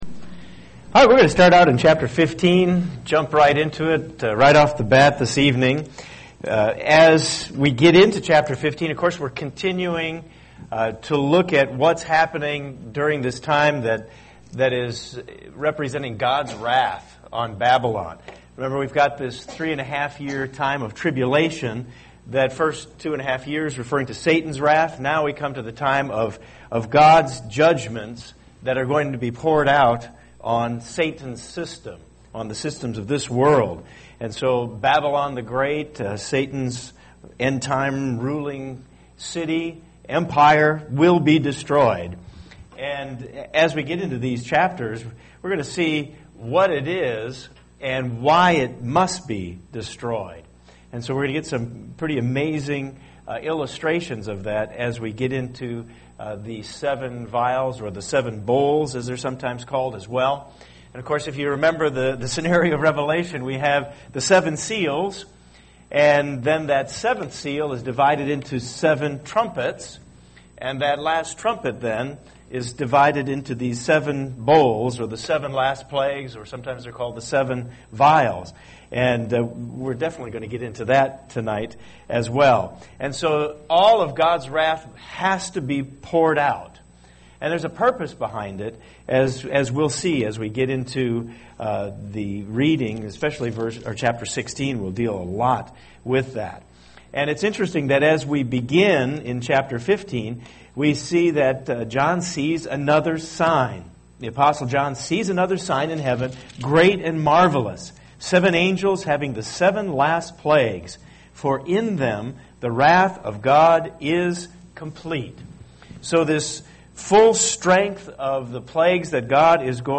Bible Study
Given in Cincinnati East, OH